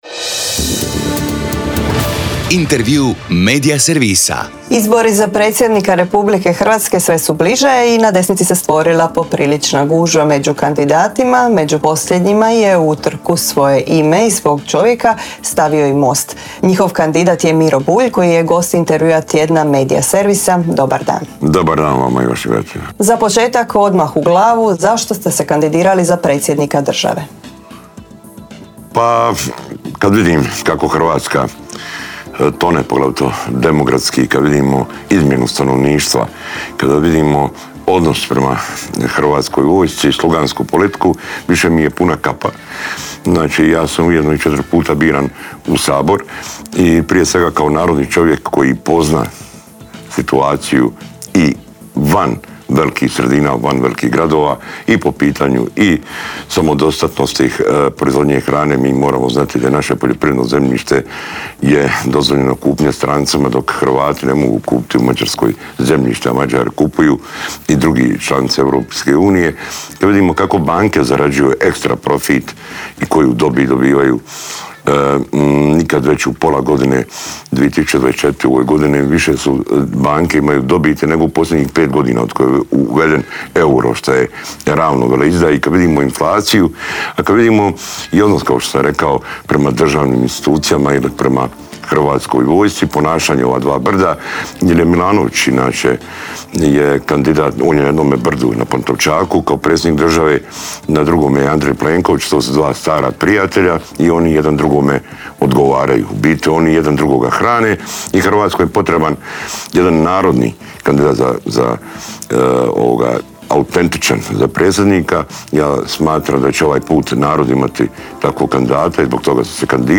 ZAGREB - Gost u Intervjuu tjedna Media servisa bio je Mostov kandidat za predsjednika Miro Bulj.